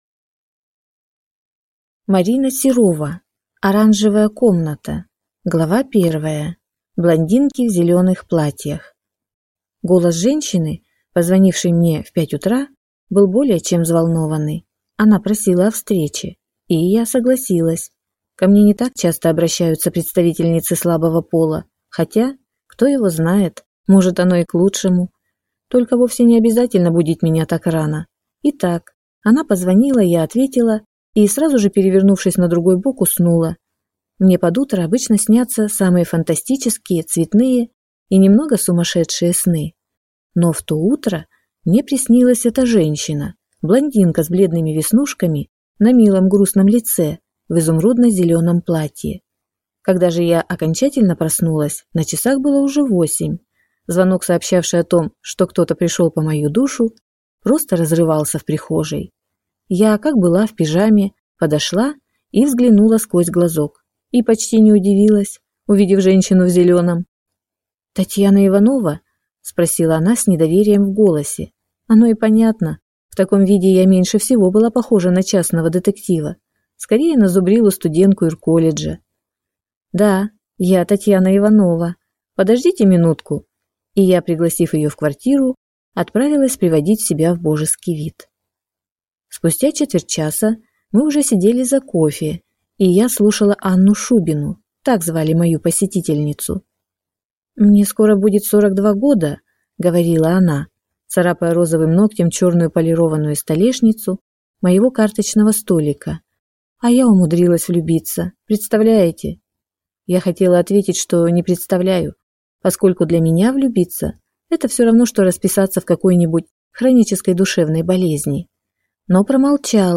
Аудиокнига Оранжевая комната | Библиотека аудиокниг
Прослушать и бесплатно скачать фрагмент аудиокниги